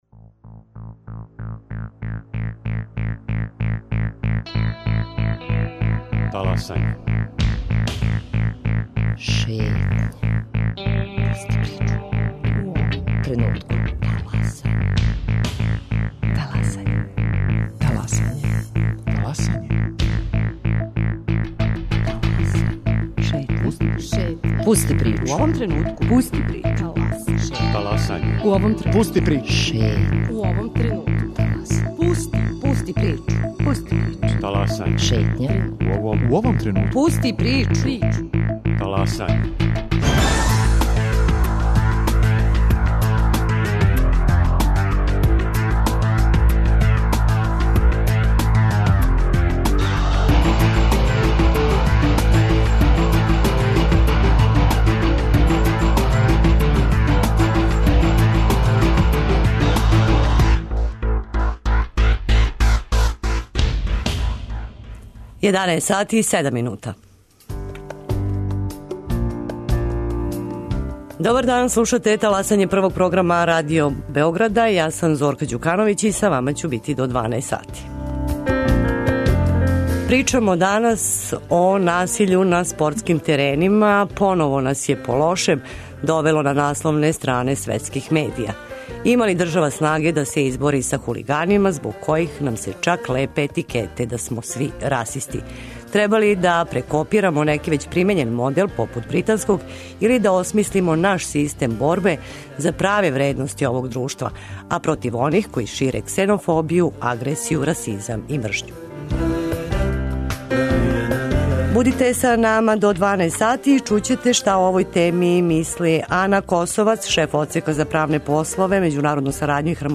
а телефоном из Лондона укључиће се и новинар